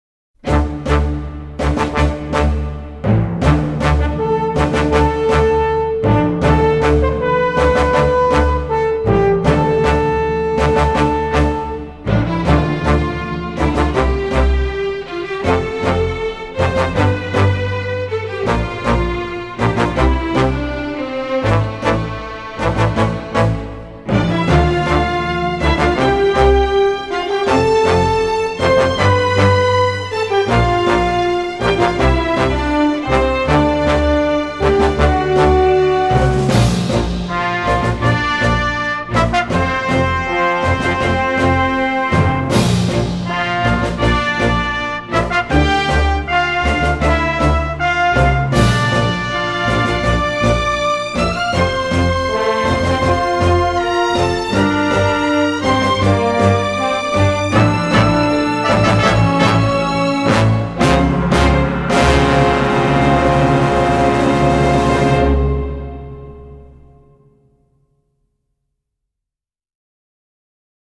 动漫原声